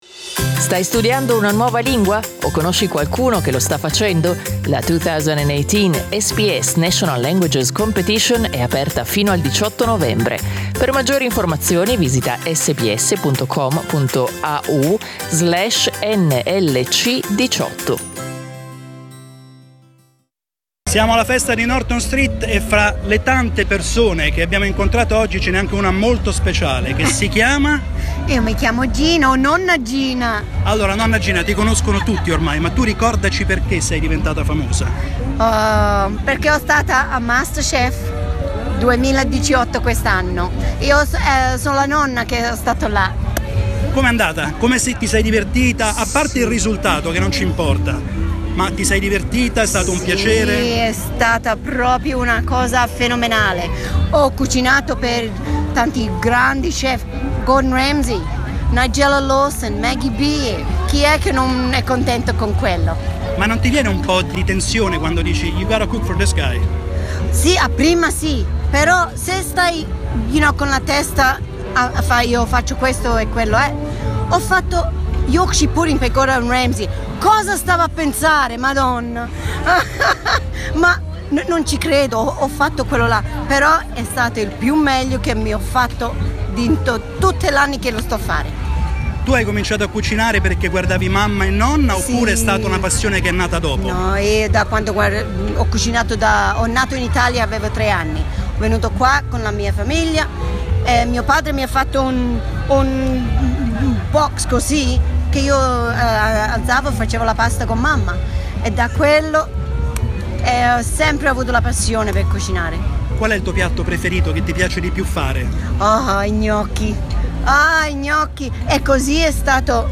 We met her last Sunday at the Norton Street Italian Festa.